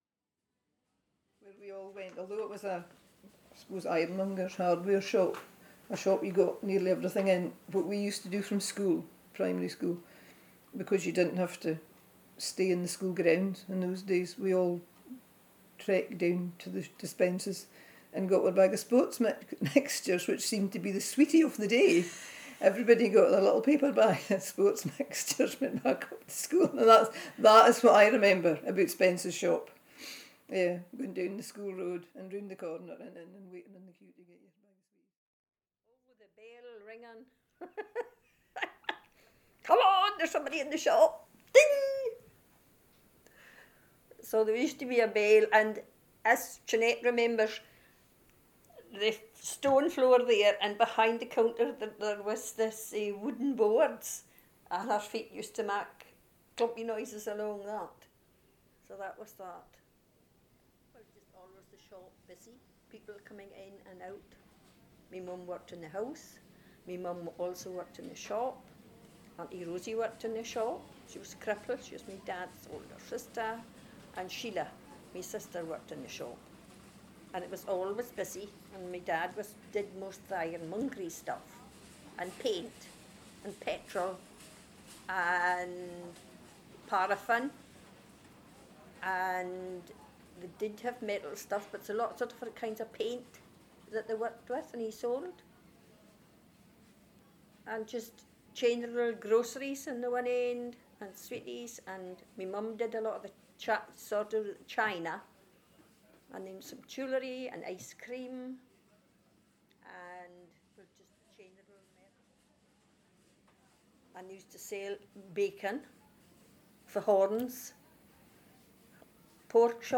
You can also listen to local people talking about the village’s history as you move around.
Press it and you will hear people talking about their experiences (which can be short – just over a minute – or longer perhaps five minutes).